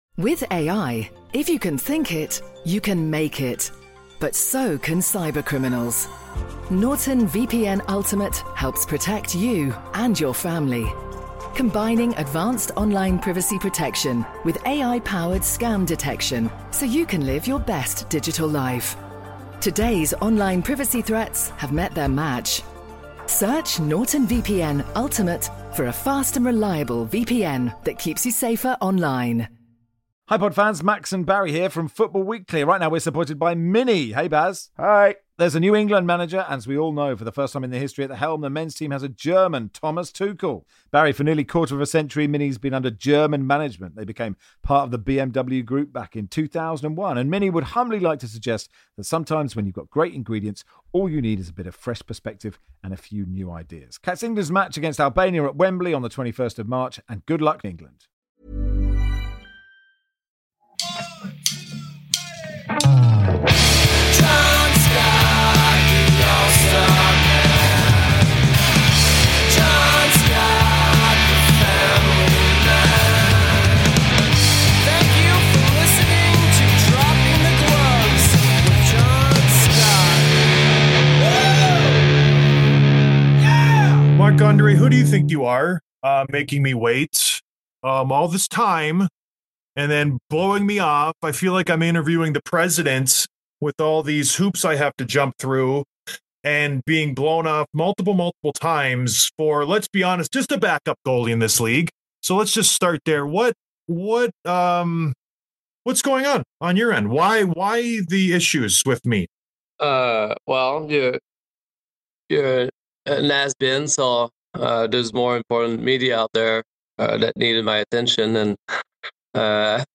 Interview with Marc-Andre Fleury, Minnesota Wild